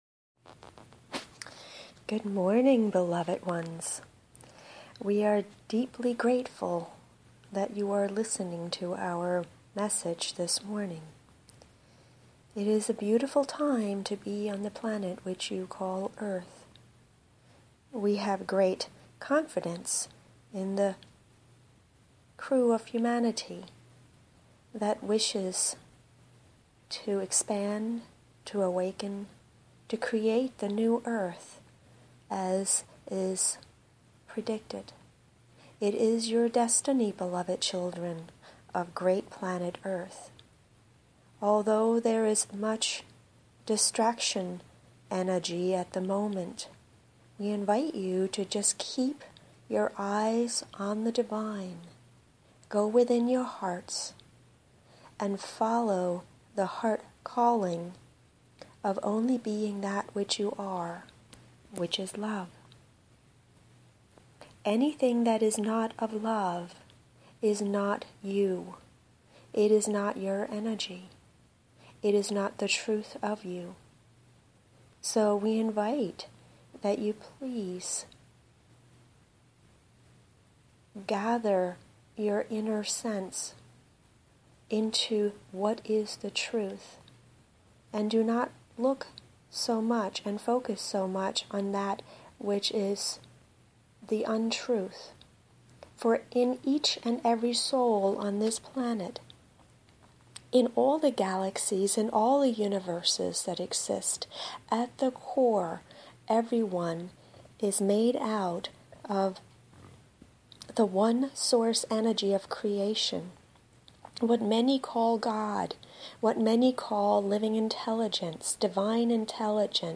Enjoy the message and the Light Language transmission at the end to gift you with remembrance, wholing and lightness.